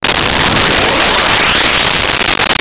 scifi14.wav